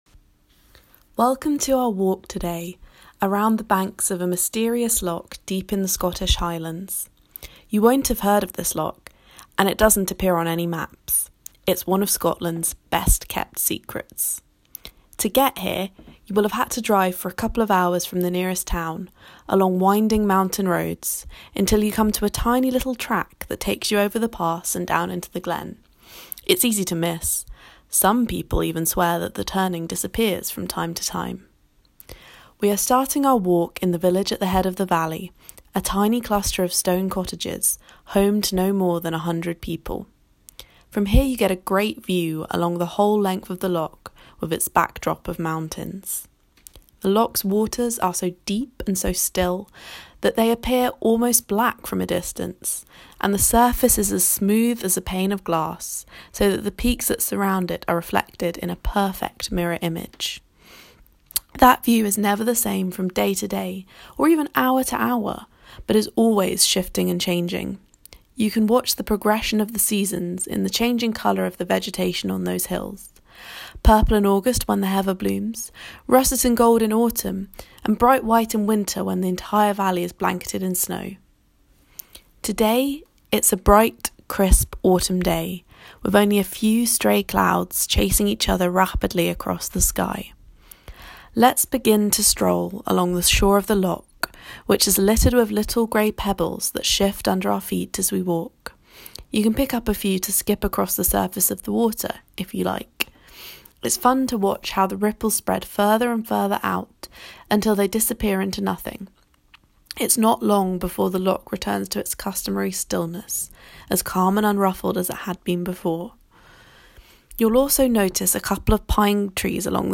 Scottish_Loch_Virtual_Walk-2.m4a